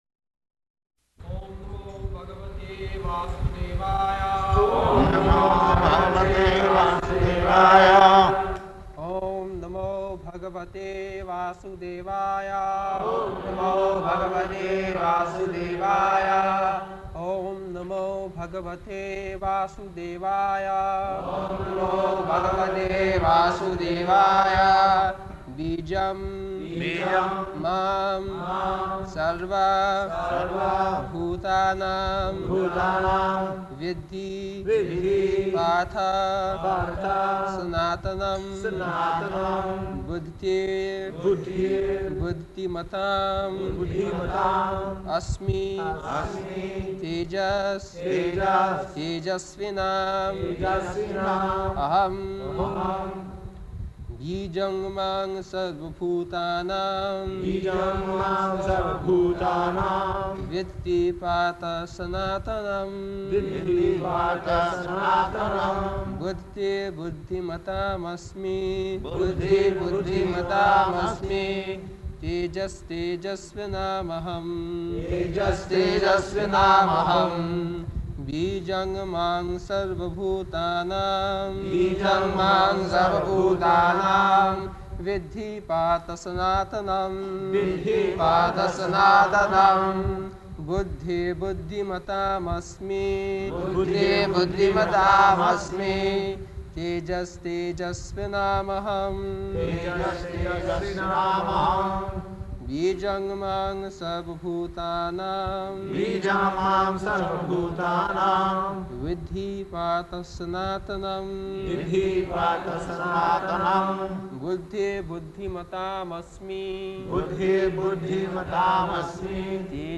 August 16th 1974 Location: Vṛndāvana Audio file
[Prabhupāda and devotees repeat] [leads chanting of verse, etc.]